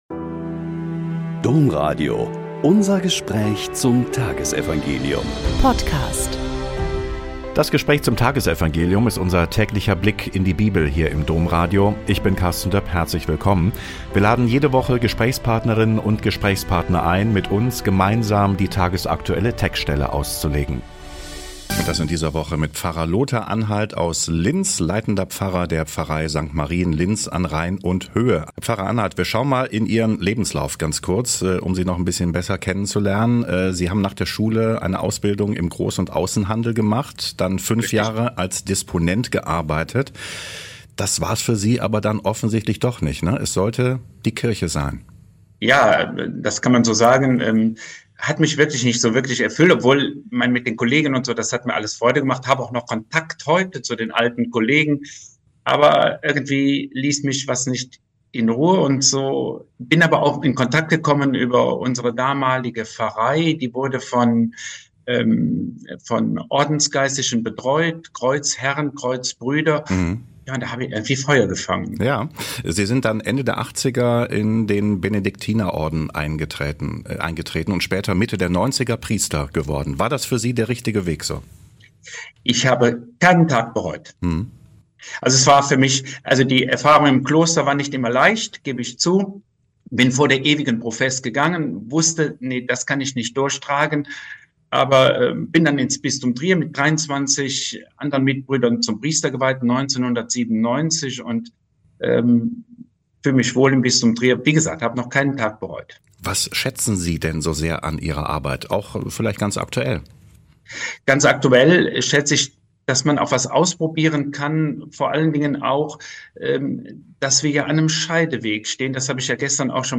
Mt 7,6.12-14 - Gespräch